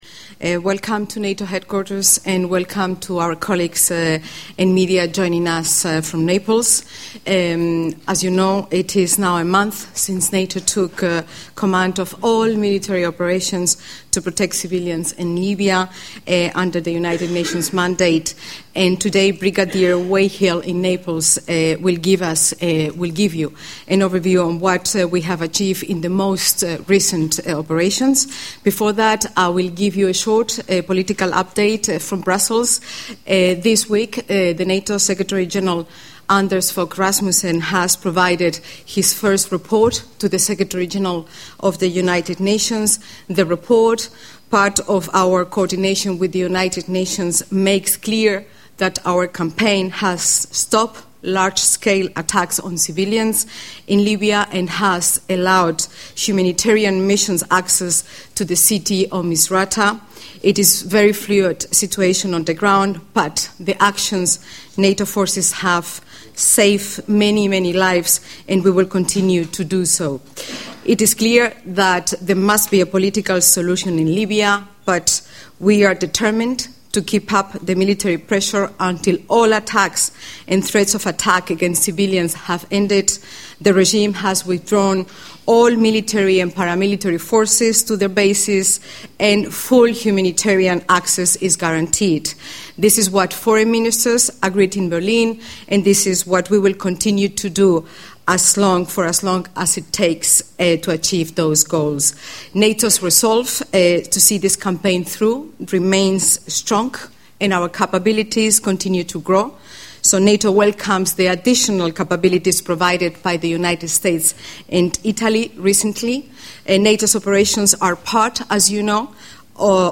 Transcript of the press briefing on Libya by NATO Deputy Spokesperson Carmen Romero and Brig. Rob Weighill, Operations Director of Operation Unified Protector